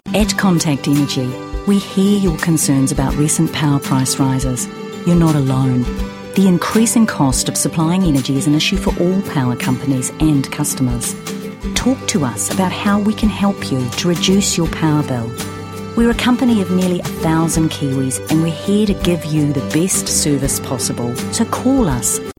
Demo
Mature Adult, Adult
English | New Zealand
conversational
smooth
well spoken
HOME STUDIO